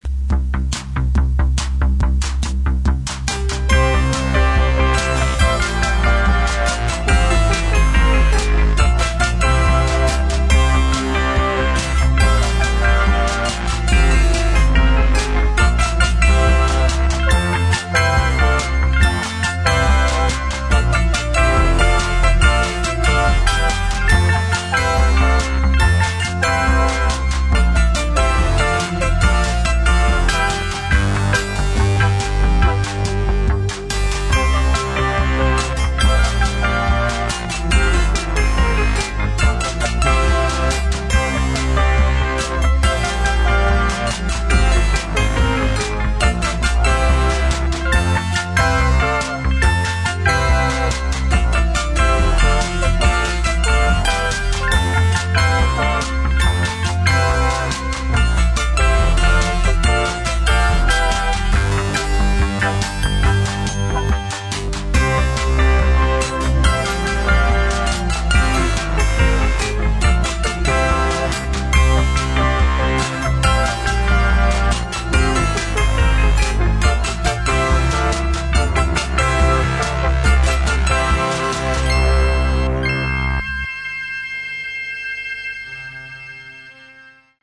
Der Schriftsteller reißt das Papier aus der Schreibmaschine, zerknüllt es und wirft es in den überfüllten Abfalleimer.